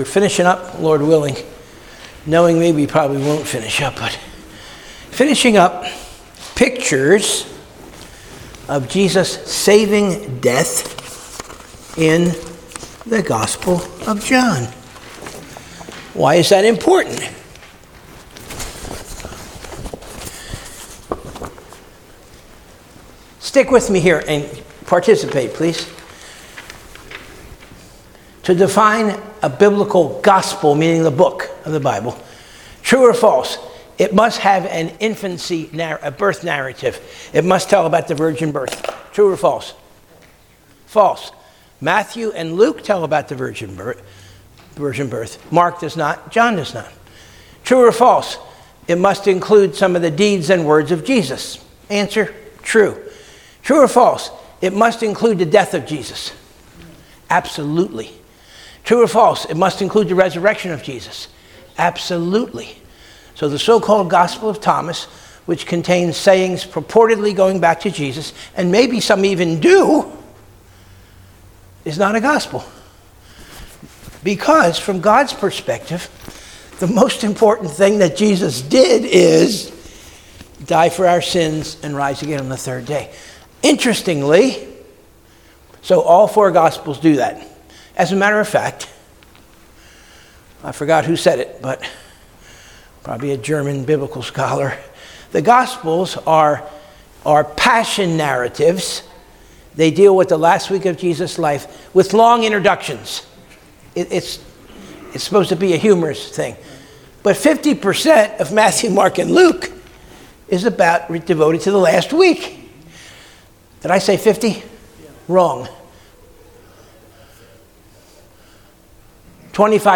Sunday School 3-8-2026 - Covenant of Grace Church